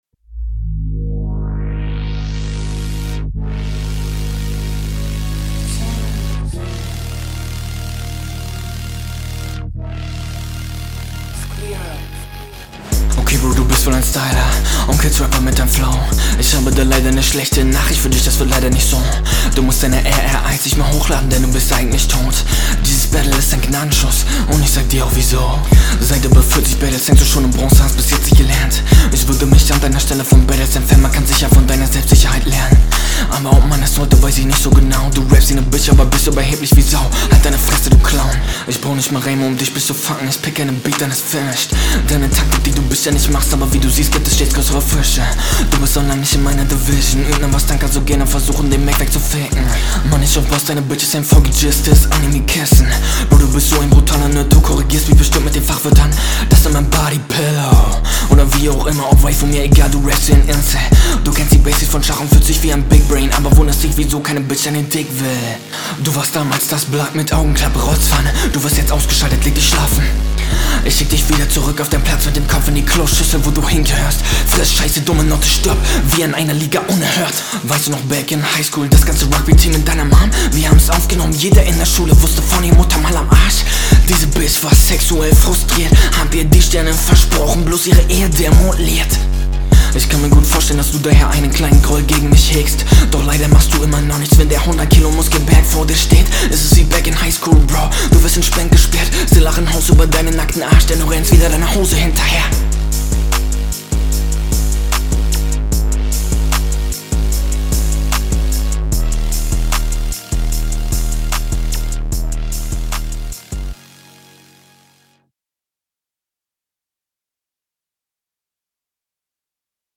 Der Ansatz ist seeeehr cool, aber das klingt alles noch etwas unausgereift, bitte mach weiter